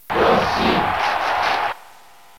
Yoshi Crowd Cheer in Dairantou Smash Brothers
Yoshi_Cheer_Japanese_SSB.ogg